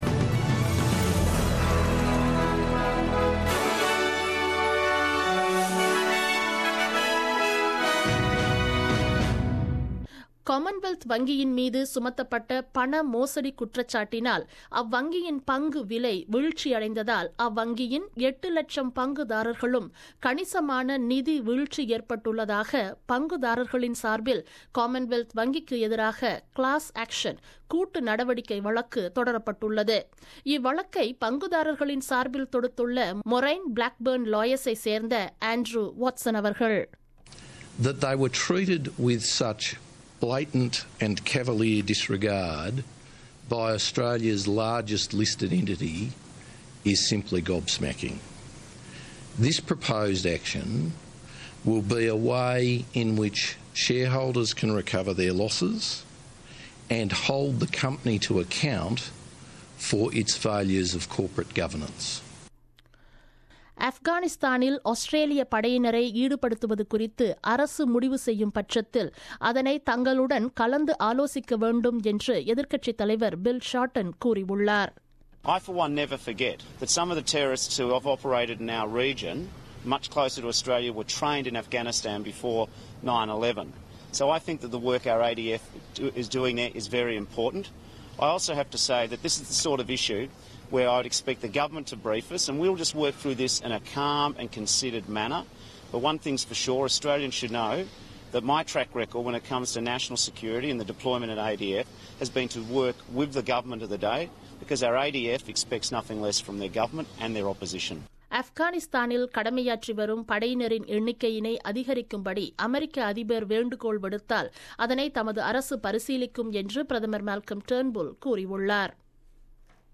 The news bulletin broadcasted on 23rd August 2017 at 8pm.